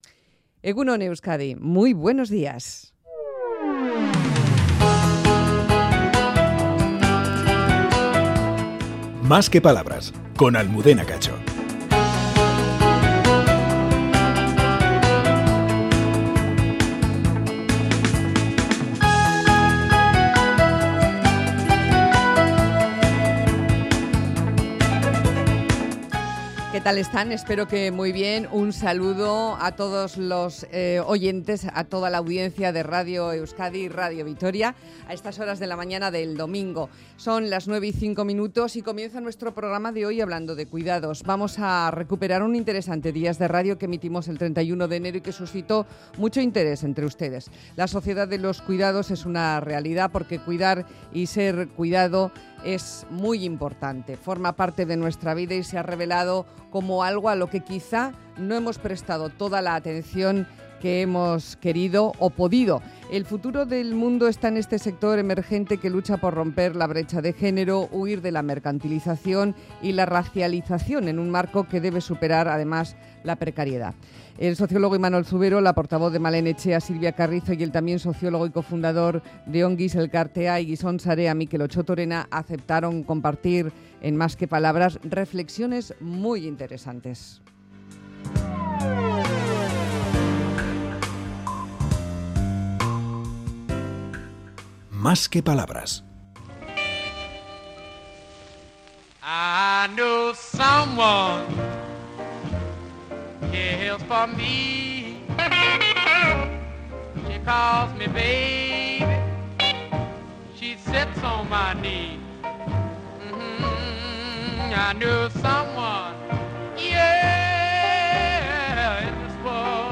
La sociedad de los cuidados. Una interesante mesa de reflexión